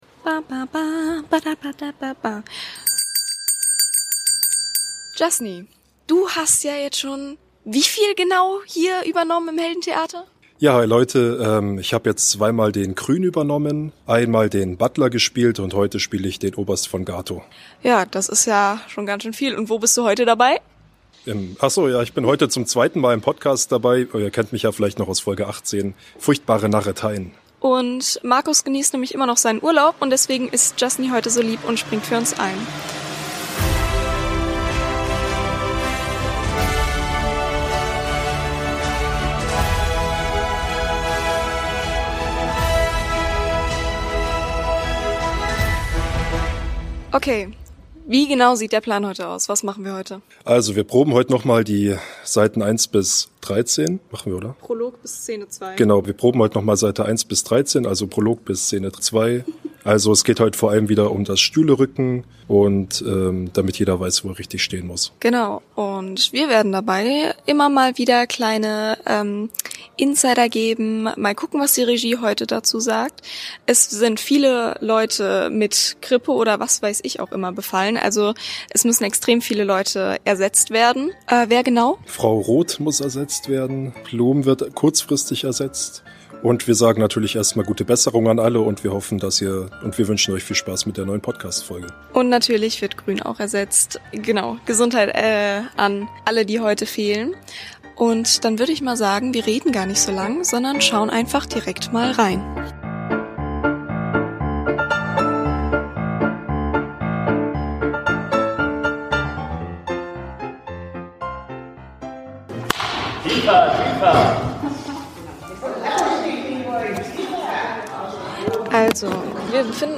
Wolltet ihr schon immer mal eine komplette Probe live miterleben? Mit allem was so an Versprechern, Korrekturen und Quatsch auf, neben und abseits der Bühne geboten wird?